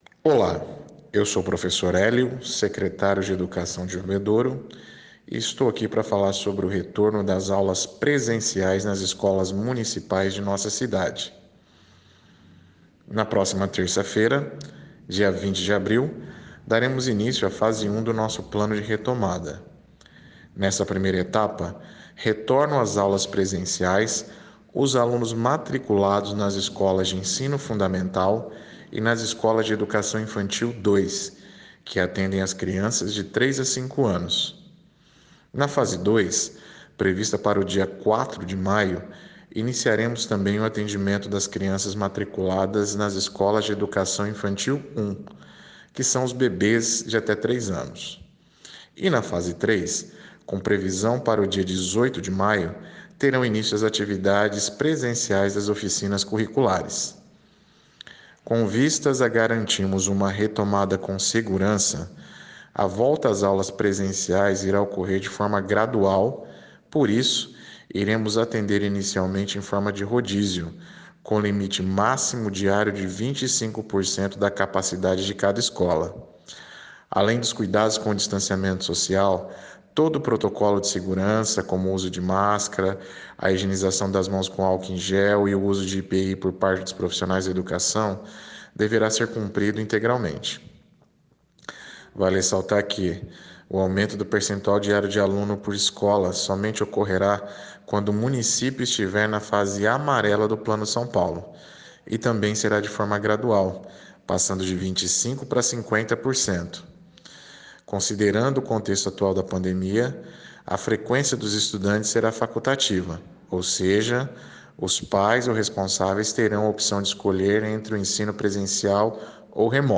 O secretário de Educação, Hélio Souza, fala do retorno das aulas presenciais, que voltam no dia 19 de abril com apenas 25% dos alunos.
Clique aqui e ouça o áudio do secretário de Educação, Hélio Souza.